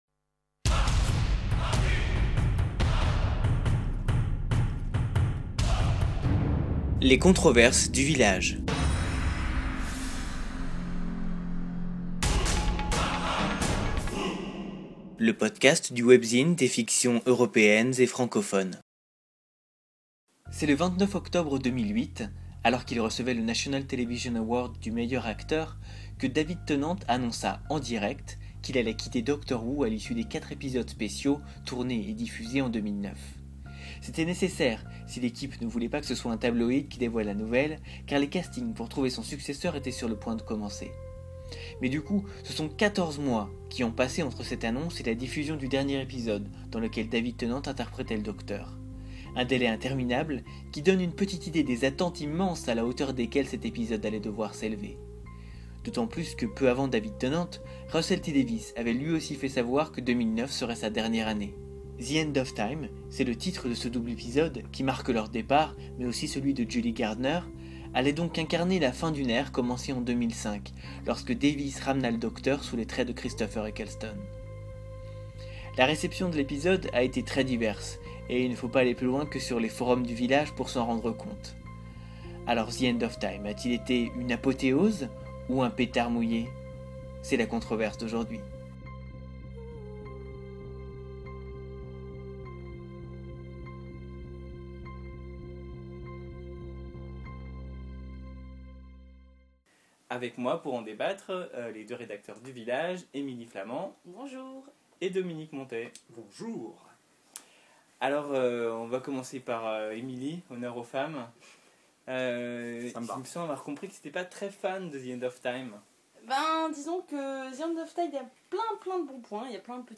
A peu près tous les mois, la rédaction se retrouvera pour débattre d’une sujet controversé. Le podcast, d’une durée comprise entre 30 et 45 minutes, reprendra ce débat enregistré dans les conditions du direct.